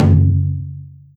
taiko_don.wav